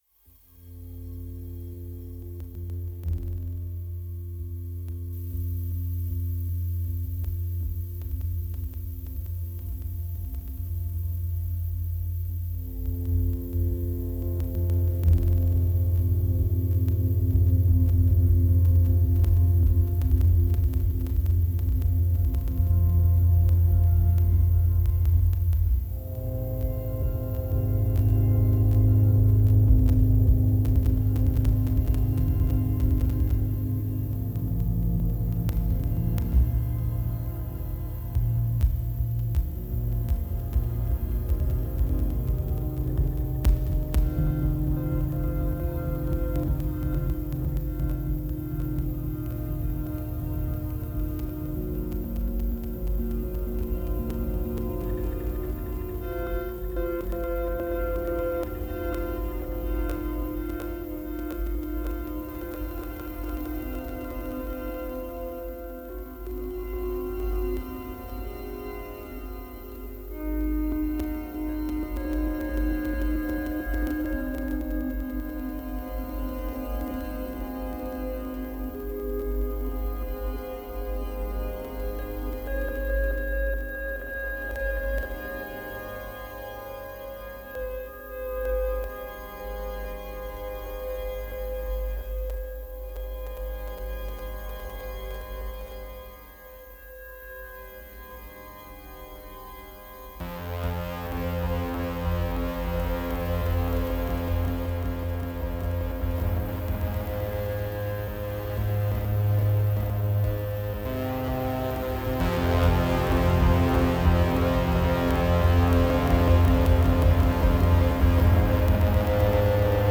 Todos estos sonidos fueron obtenidos de grabaciones en vivo.